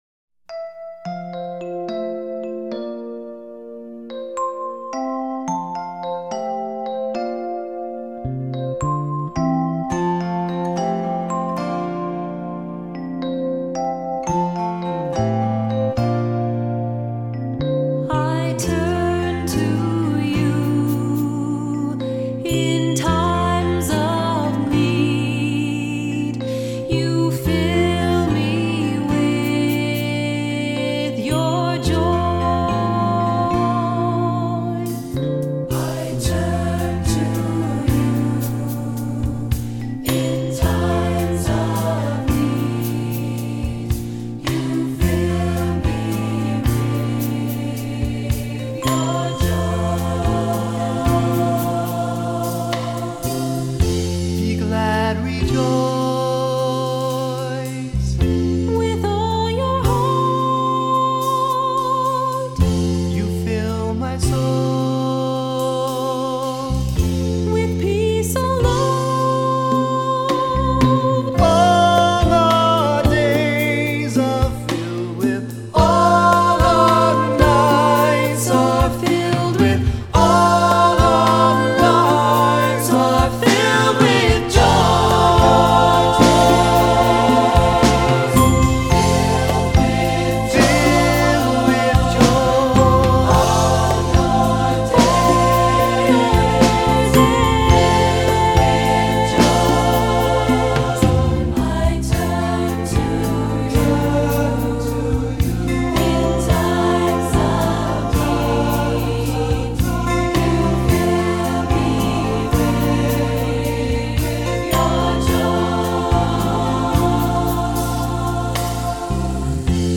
Voicing: Assembly,Baritone Soloist,Cantor